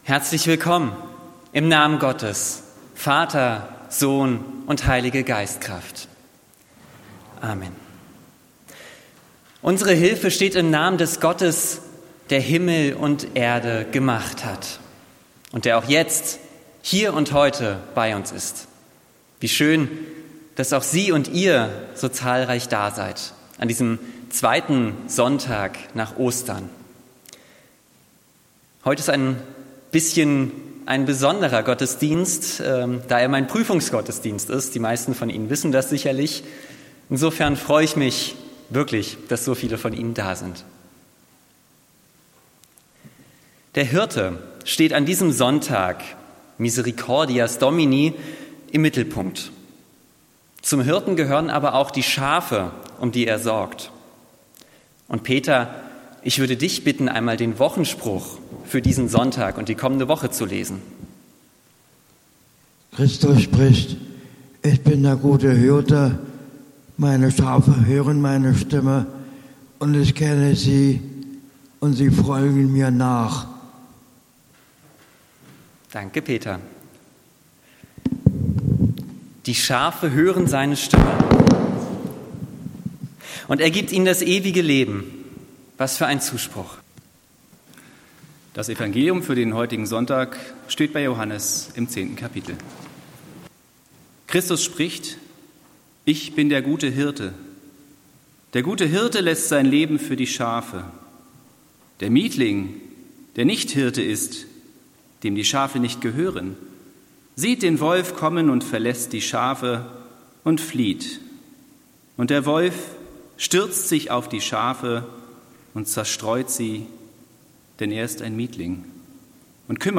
Predigt am Sonntag Miserikordias Domini 19.4.2026